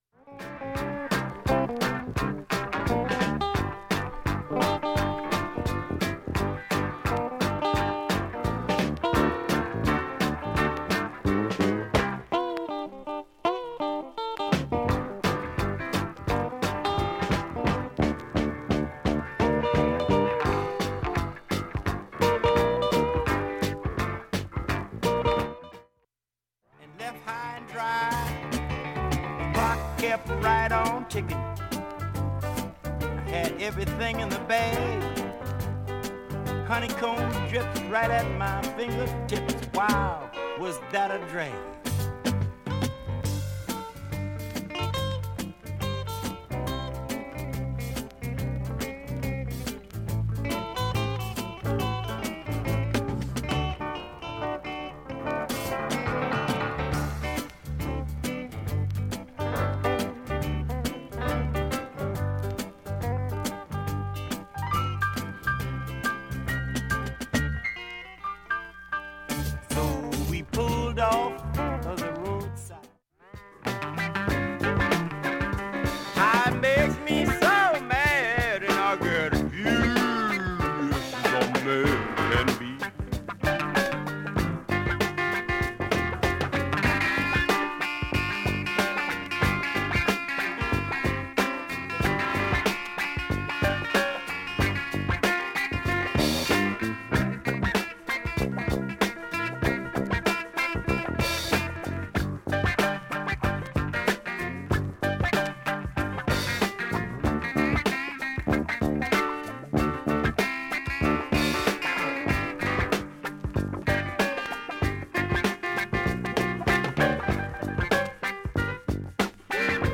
A-7中盤に５回プツと数回シャリ音出ます。 現物の試聴（上記録音時間２分）できます。